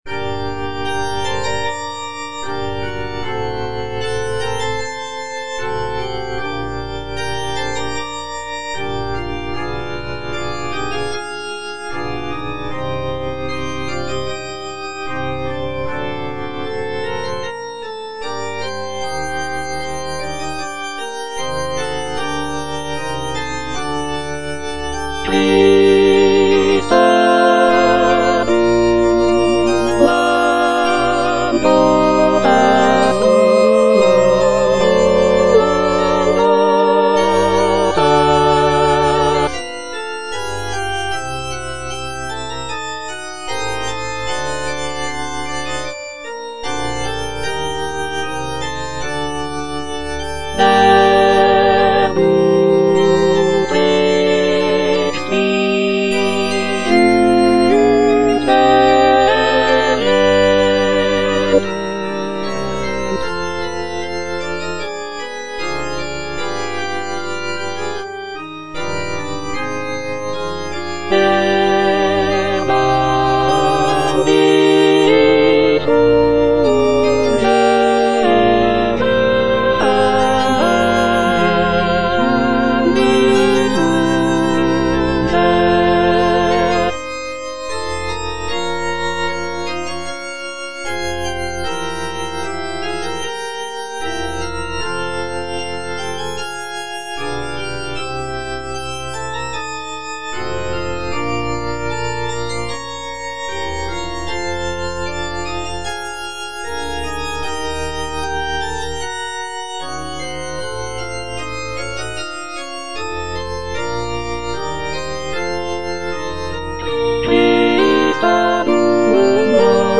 Choralplayer playing Cantata
Alto (Emphasised voice and other voices) Ads stop